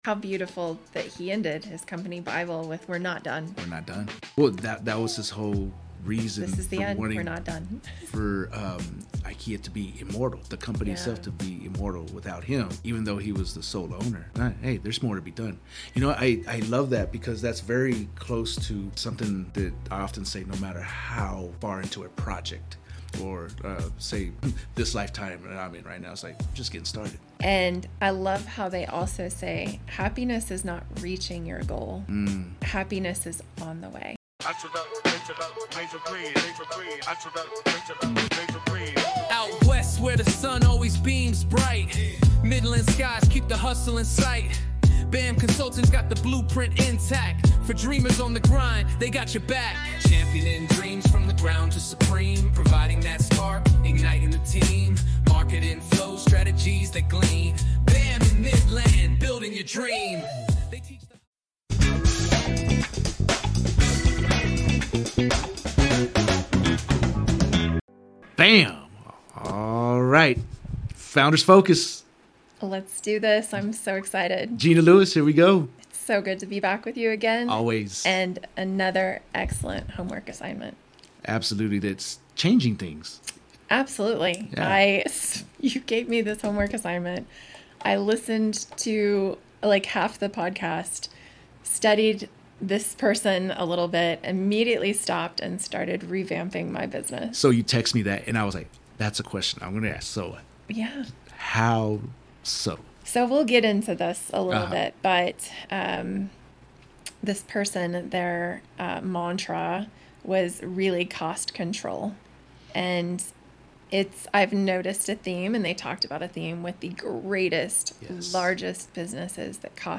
Play Rate Listened List Bookmark Get this podcast via API From The Podcast The BAM BIZ TALK podcast delivers insightful discussions on emerging technologies, innovative products/services, industry trends, and effective business strategies.